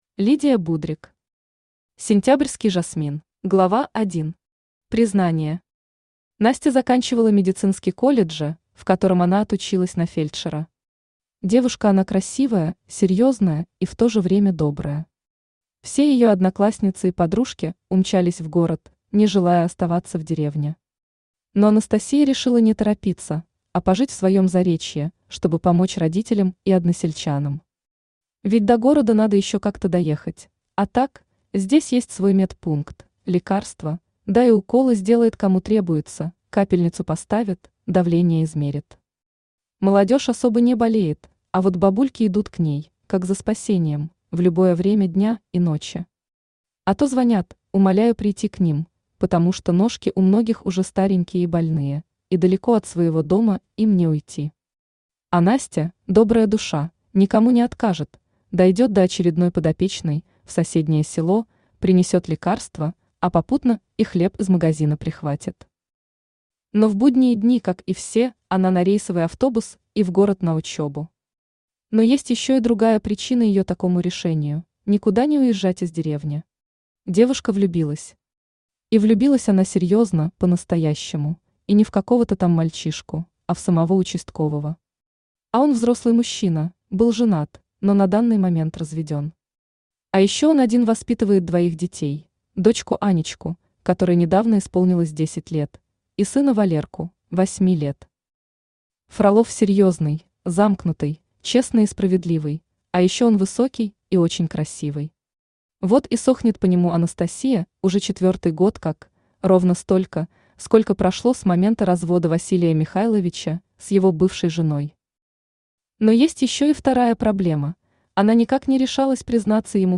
Аудиокнига Сентябрьский жасмин | Библиотека аудиокниг
Aудиокнига Сентябрьский жасмин Автор Лидия Петровна Будрик Читает аудиокнигу Авточтец ЛитРес.